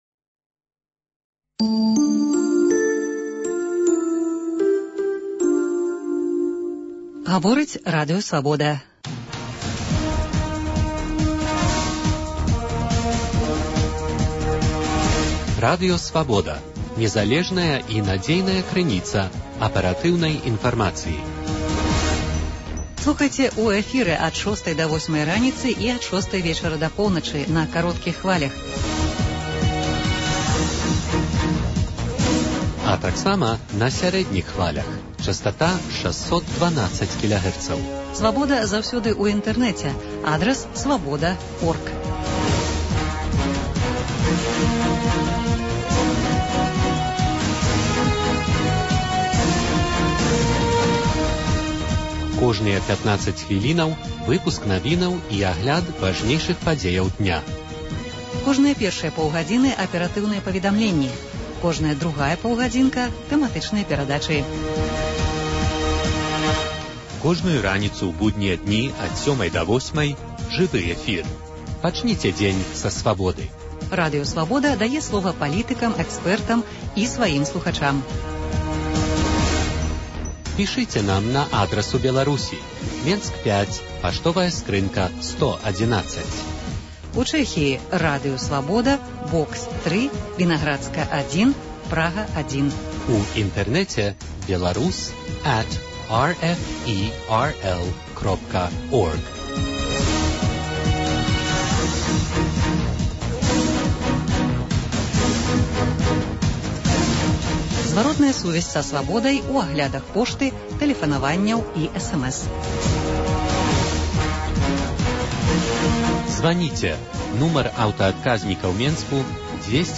* Паўтор жывога эфіру пятніцы ад 18.00. * Эфір грамадзкага рэдактара